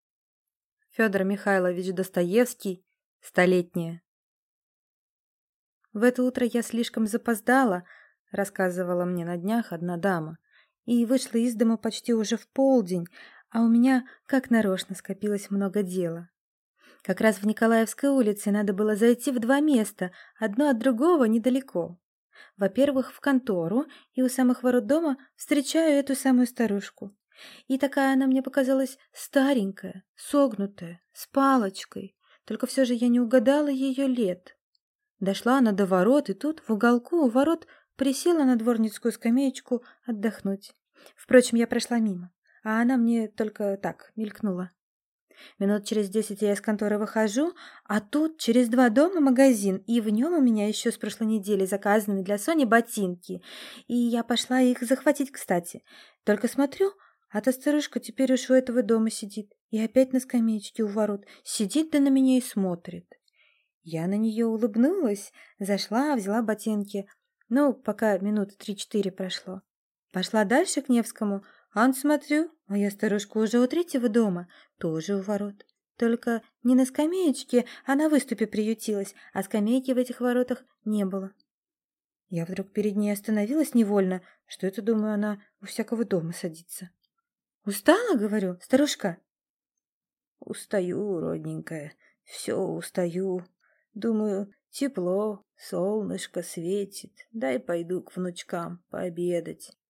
Аудиокнига Столетняя | Библиотека аудиокниг